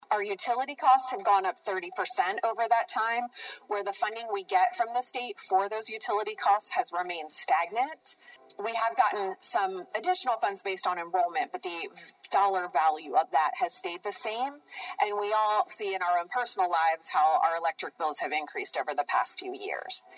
The meeting was held at Indian River High School.